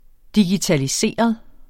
Udtale [ digitaliˈseˀʌð ]